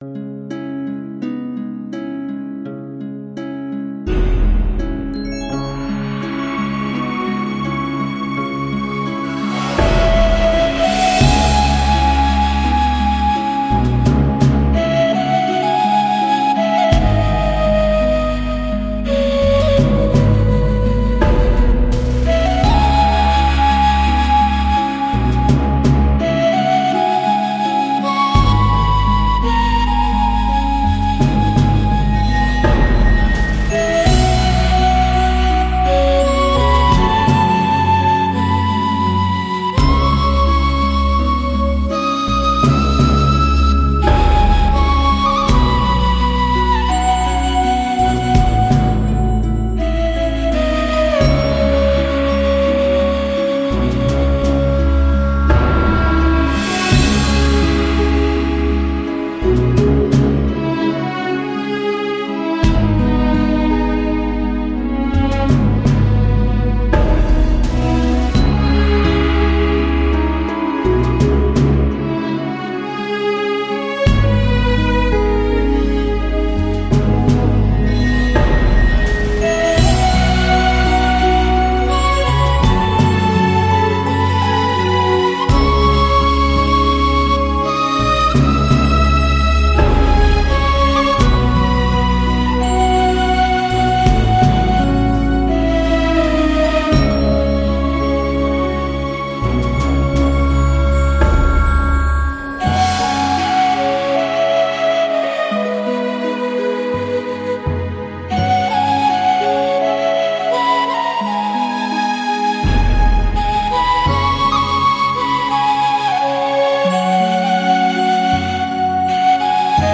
纯音